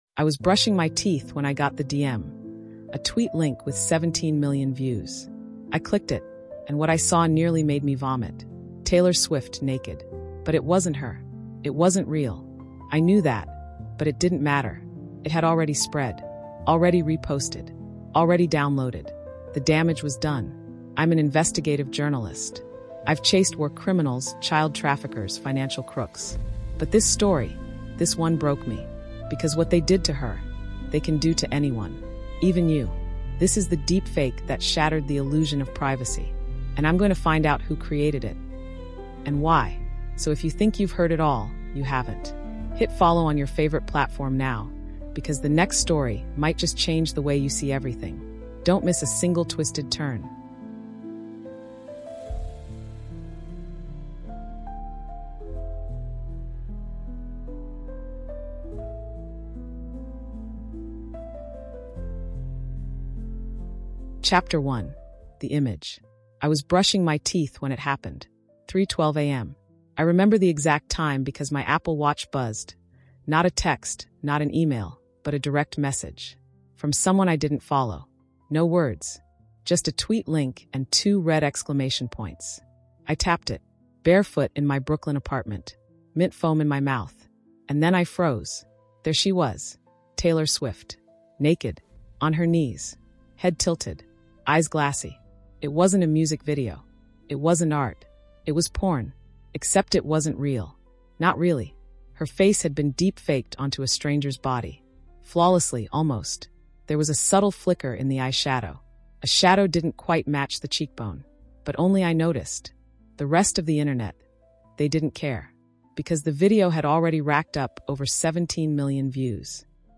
This investigation reveals how manipulation through AI-generated content has become a tool of exploitation, shaking the foundations of privacy in our digital age. Join our female tech journalist as she examines the psychological tactics behind the viral spread of these non-consensual deepfakes, exploring the hidden mechanics of control and the sinister figures profiting from this abuse.